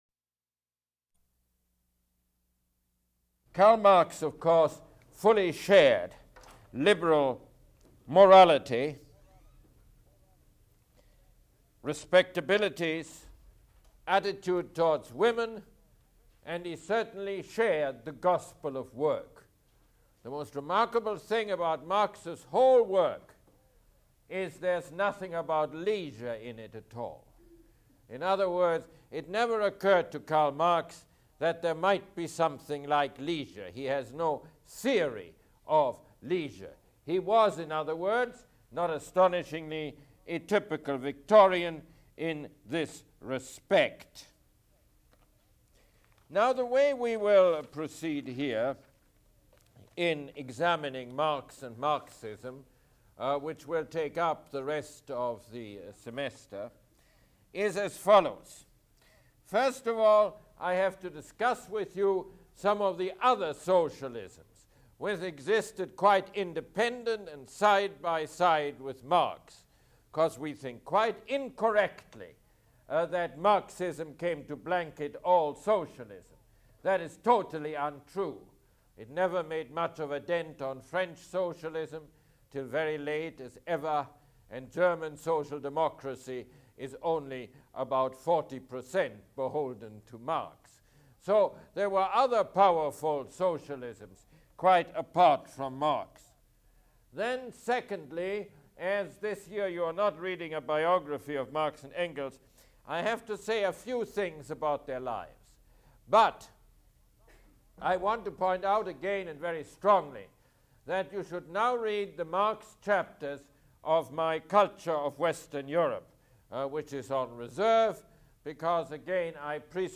Mosse Lecture #28